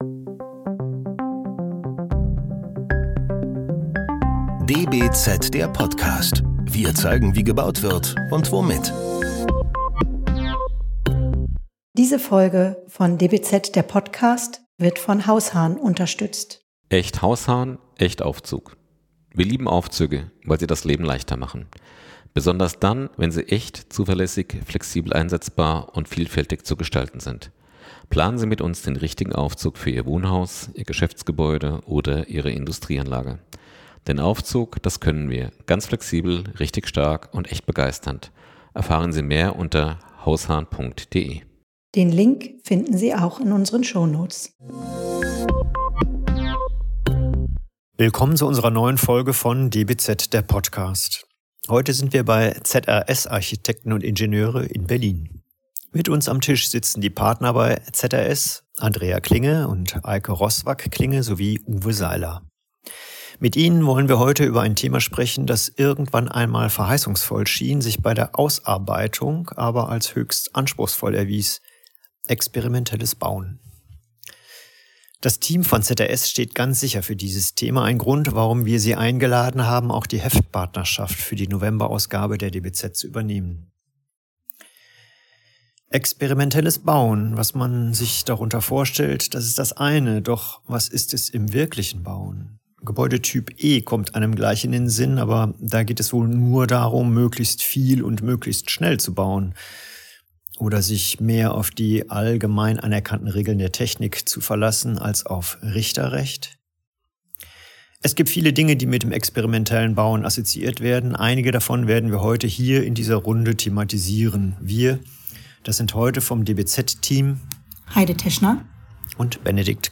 Das DBZ Team bespricht im Podcast relevante Fragen der Architektur, der Bautechnik und der Baupraxis und lädt Mitdenker, Vordenker und Querdenker der Branche ein, mitzudiskutieren.